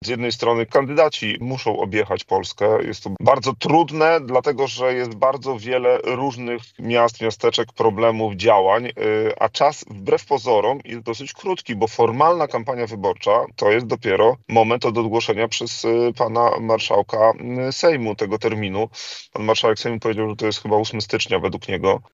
O wyborach prezydenckich i kandydatach mówił poseł Prawa i Sprawiedliwości Dariusz Stefaniuk w porannej rozmowie Radia Lublin.